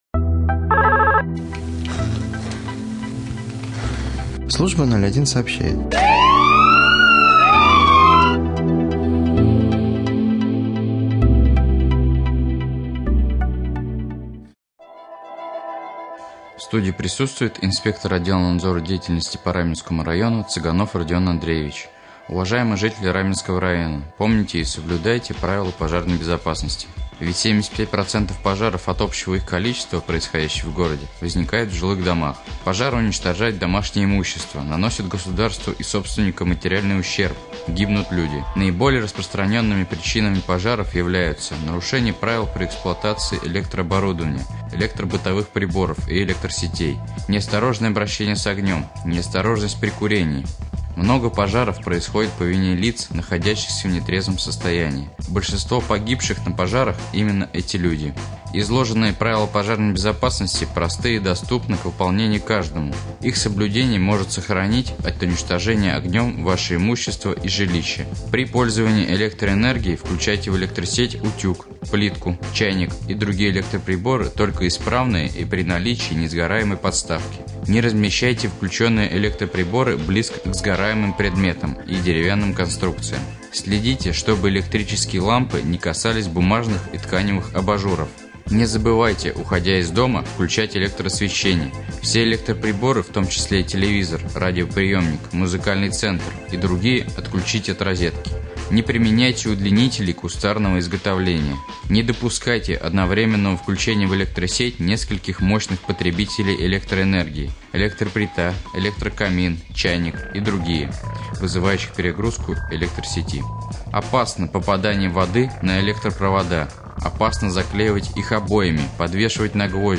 24.12.2015г. в эфире Раменского радио